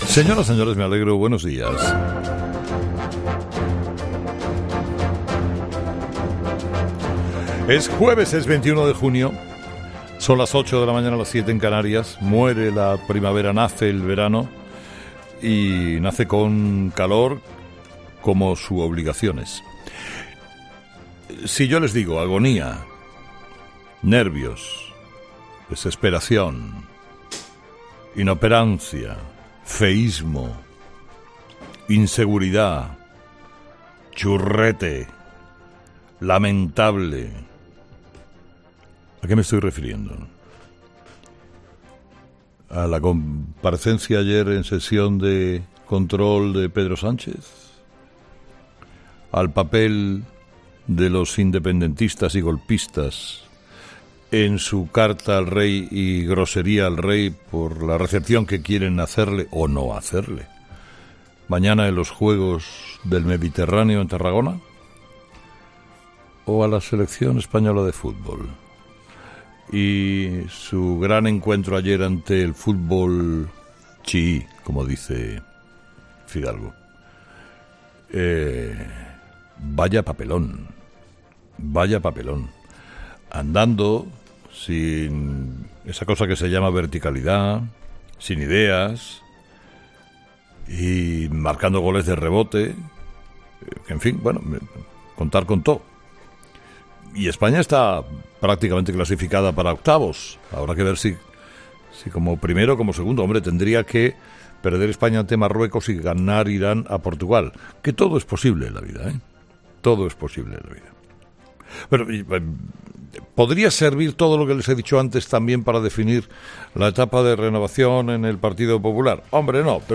Monólogo de las 8 de Herrera
Con Carlos Herrera